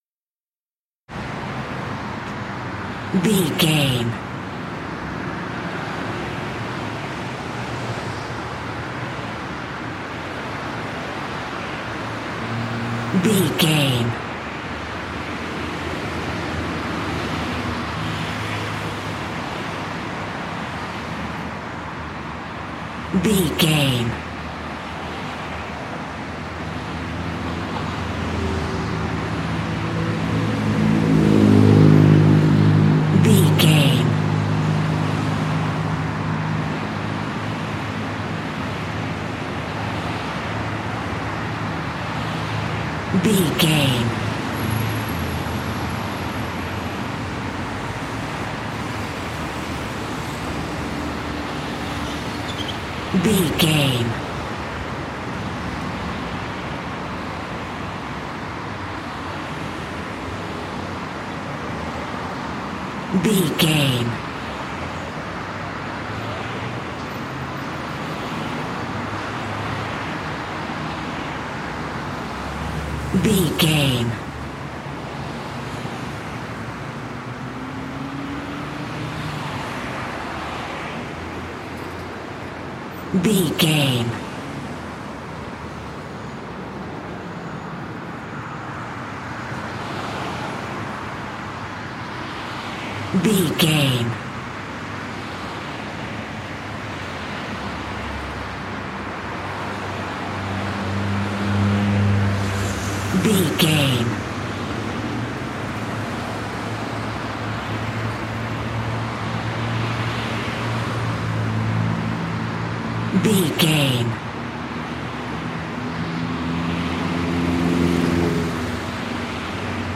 Traffic highway
Traffic highway 60
Sound Effects
urban
ambience